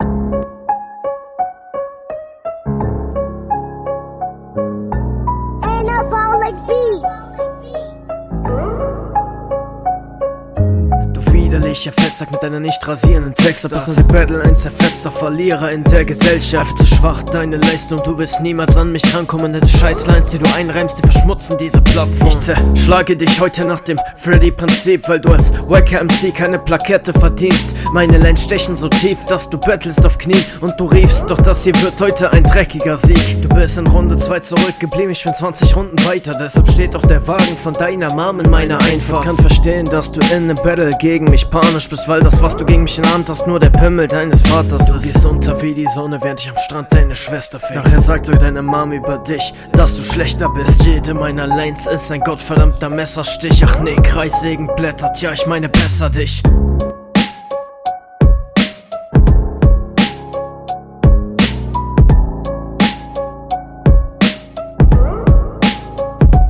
Cooler Beat!
Youtube grüßt :D. Abmische wie die anderen Runden zu schwach.